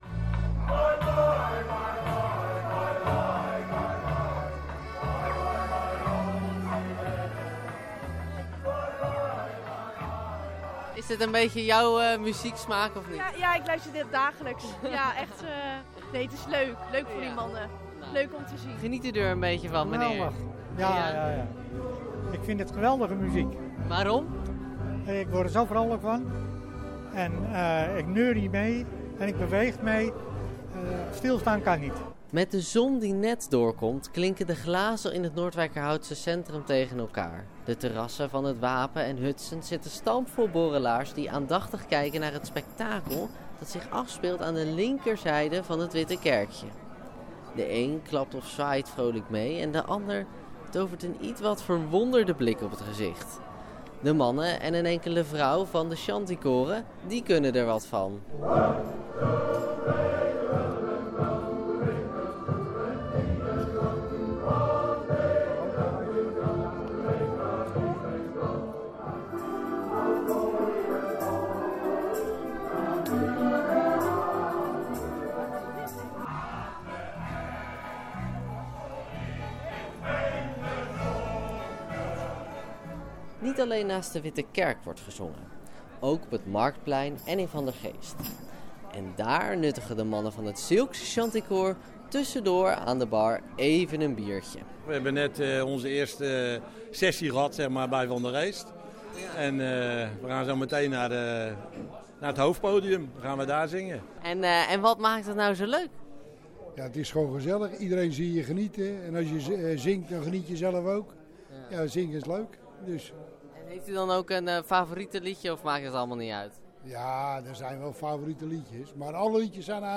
Zes Zeemanskoren uit de Bollenstreek en daarbuiten zongen zaterdagmiddag de longen uit hun lijf.
Shanty_mixdown.mp3